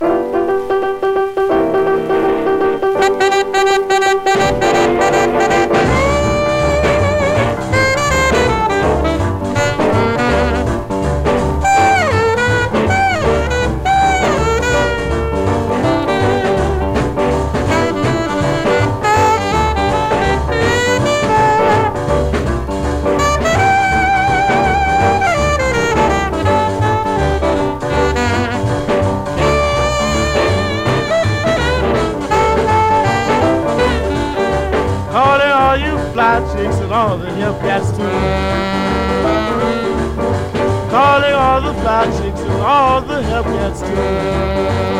R&B, Blues, Jump, Louisiana　USA　12inchレコード　33rpm　Mono